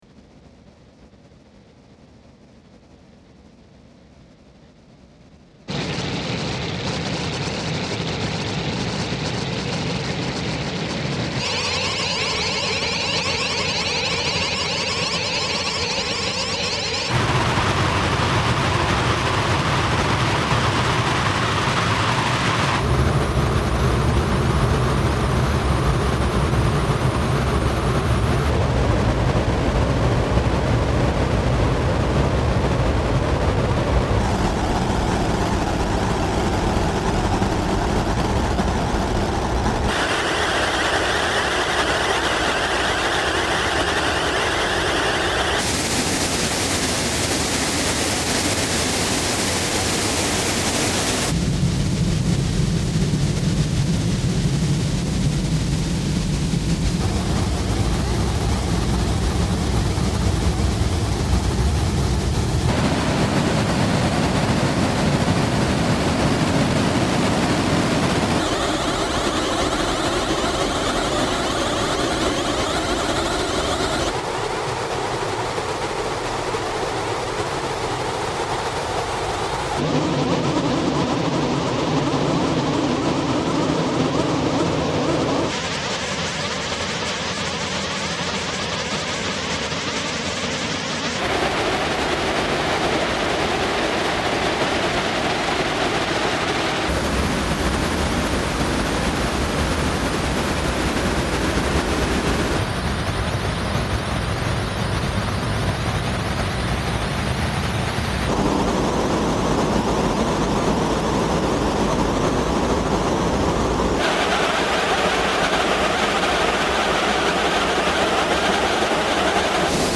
Listening to them side by side, it’s clear that the songs are similar enough that they can go back to back fairly seamlessly, but at the same time manage to set a considerably different tone in each of their various parts.